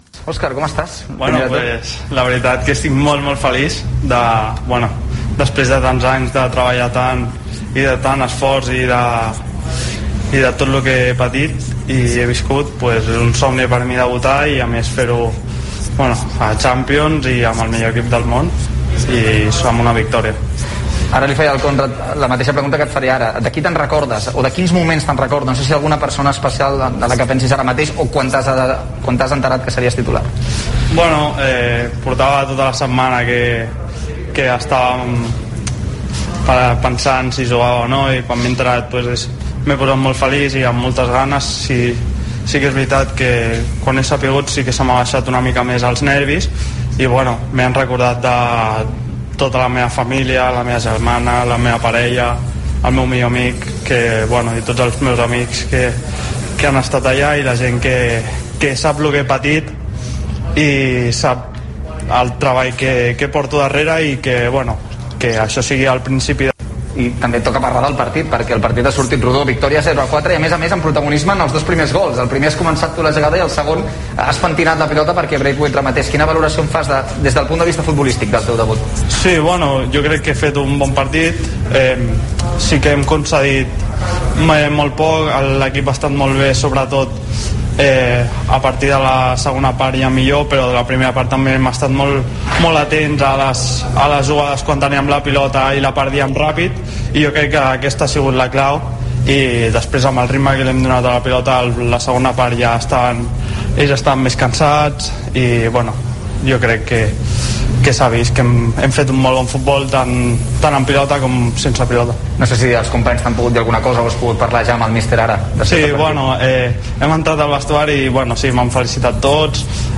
AUDIO: Escucha las declaraciones de los canteranos después de su actuación destacada en Kiev.